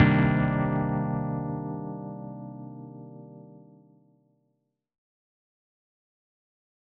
005_Min9.L.wav